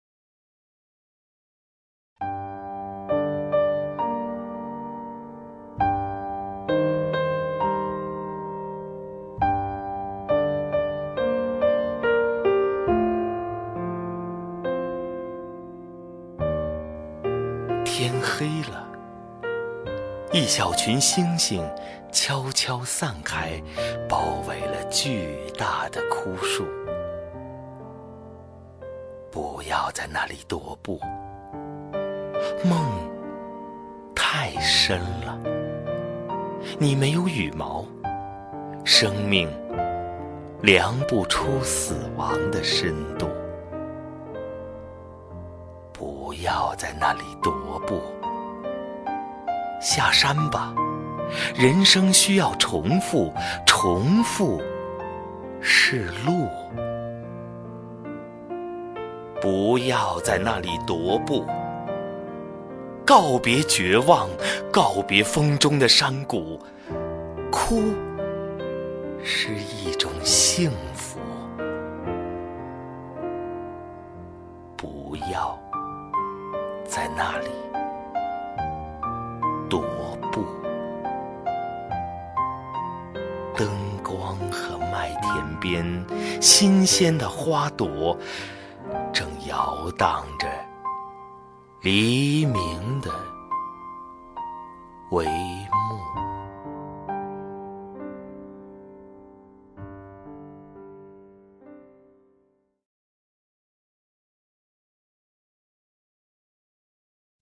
首页 视听 名家朗诵欣赏 赵屹鸥
赵屹鸥朗诵：《不要在那里踱步》(顾城)